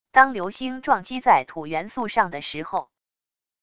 当流星撞击在土元素上的时候wav音频